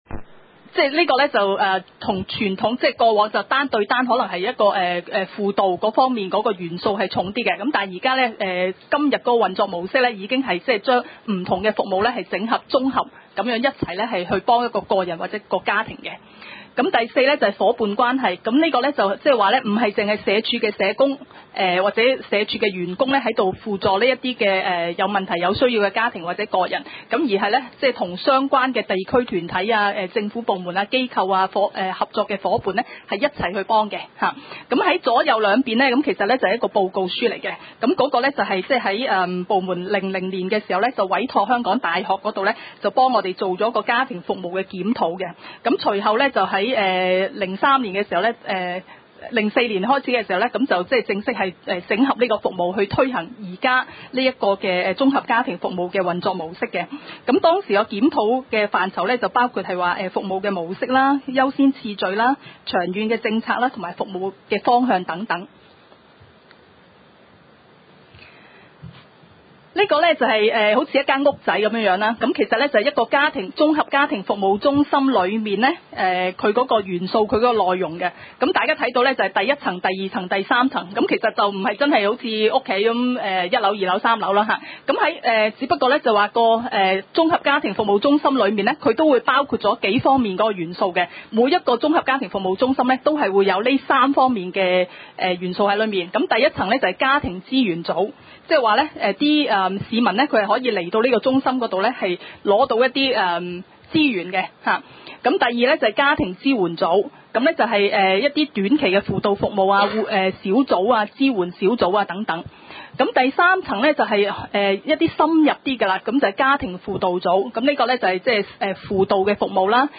第三屆觀塘區議會屬下 社會服務委員會第十四次會議記錄 日 期 : 2009 年 12 月 15 日 ( 星期二 ) 時 間 : 下午 2 時 30 分 地 點 : 九龍觀塘同仁街 6 號觀塘政府合署 3 樓觀塘民政事務處會議室 議 程 討論時間 I. 通過上次會議記錄 II. 社會福利服務介紹 ?/span>家庭服務 0:37:20 III.